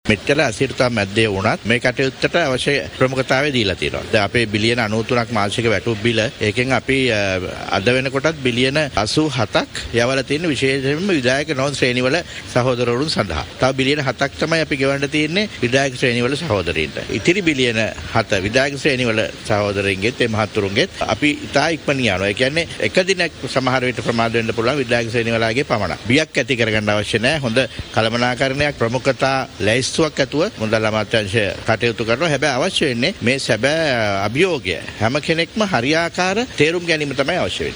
ඔහු මේ බව කියා සිටියේ මුදල් අමාත්‍යාංශයේදී අද මාධ්‍ය වෙත අදහස් දක්වමින්.